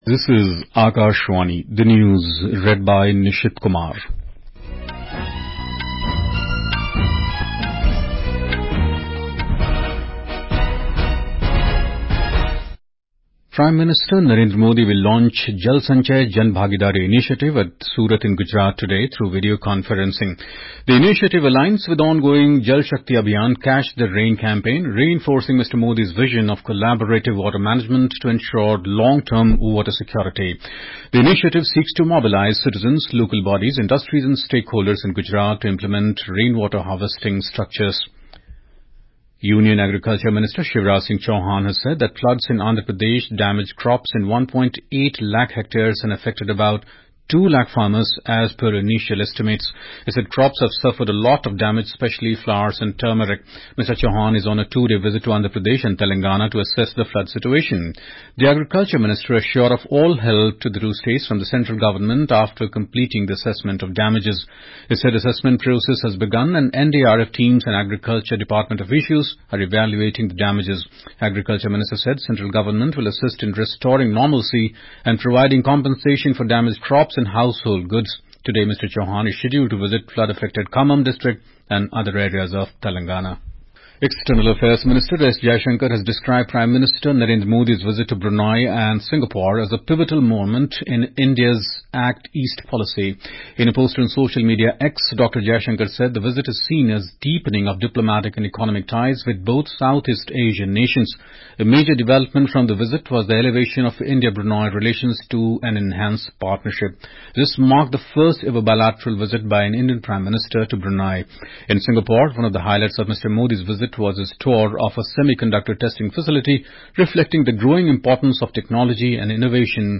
National Bulletins
Hourly News